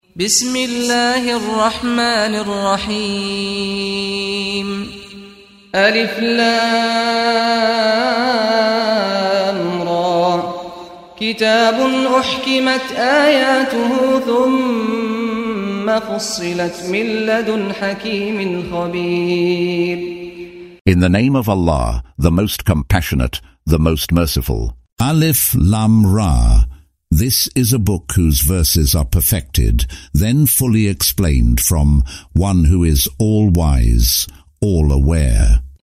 Audio version of Surah Hud in English, split into verses, preceded by the recitation of the reciter: Saad Al-Ghamdi.